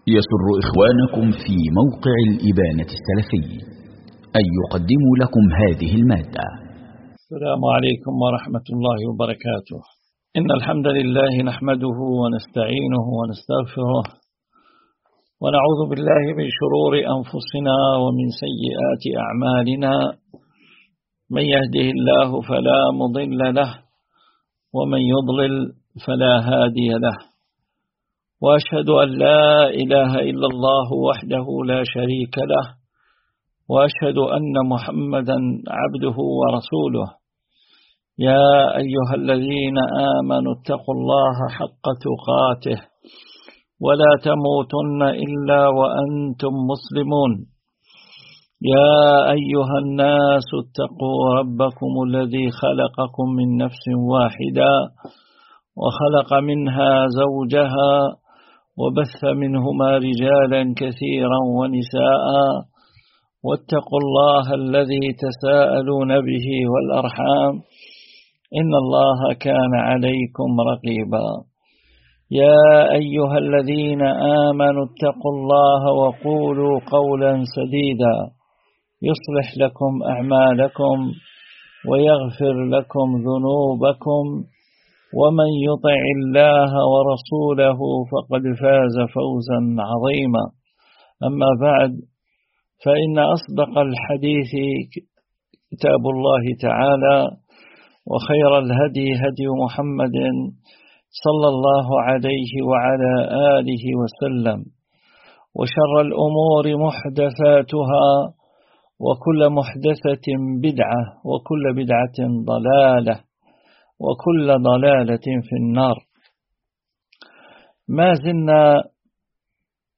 شرح أحكام الأضحية والذكاة الدرس 7